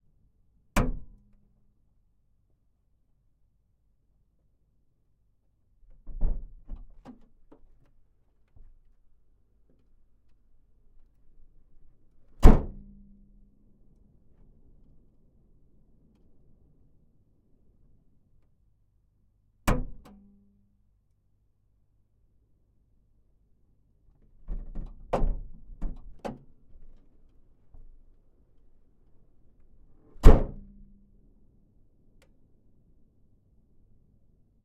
Pole Position - Toyota Land Cruiser LX Turbo 1985